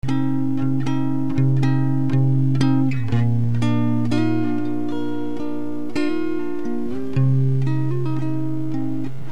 Original unprocessed signal
There is static ‘hiss’ audible in the original unprocessed signal.